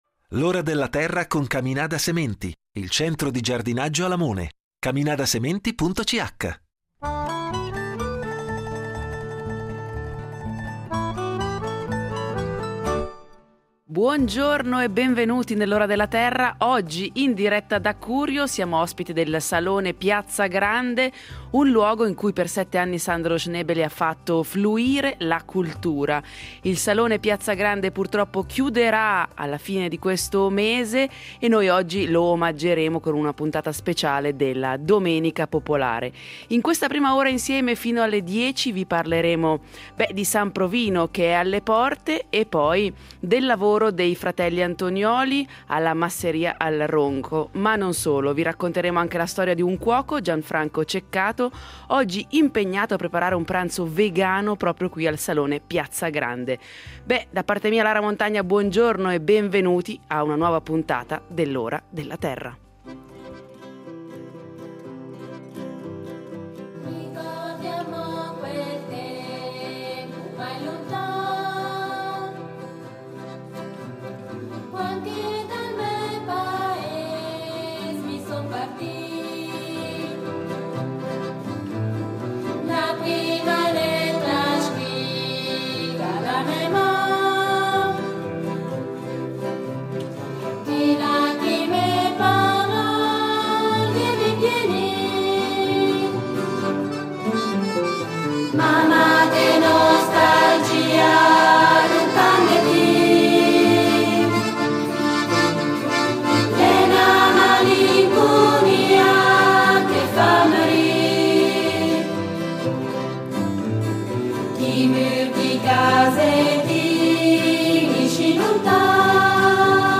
In diretta da Curio, ospiti del Salone Piazza Grande